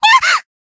贡献 ） 分类:蔚蓝档案语音 协议:Copyright 您不可以覆盖此文件。
BA_V_Hanako_Battle_Damage_1.ogg